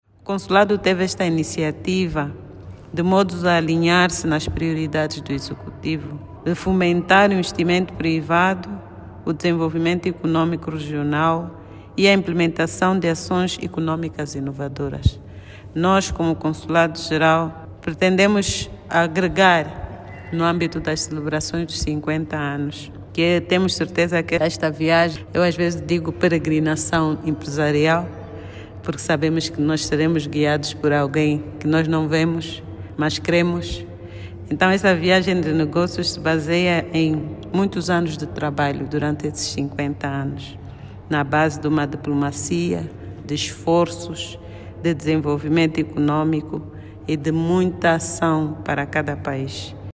A cônsul-geral de Angola em Cape Town, Elsa Caposso, explicou que a iniciativa responde ao desafio lançado pelo Presidente da República, que tem reiterado a importância de uma diplomacia orientada para resultados económicos.